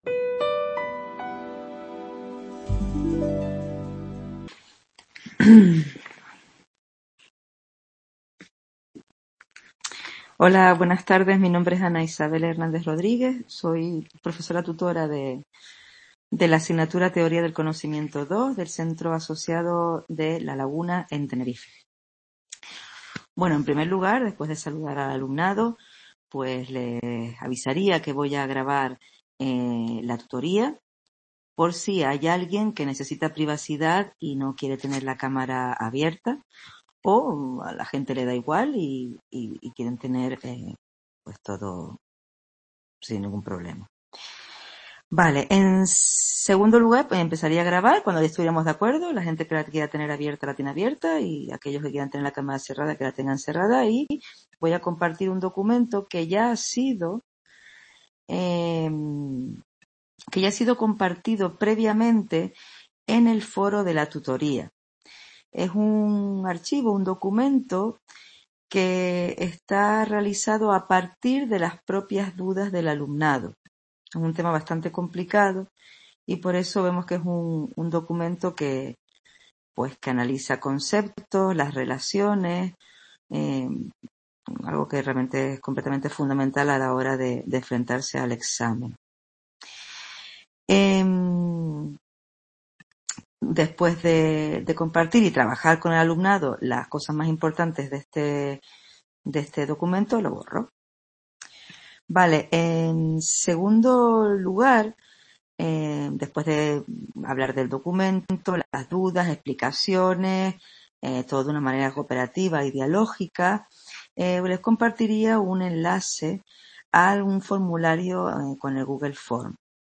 Simulación de grabación de tutoría CIT
Video Clase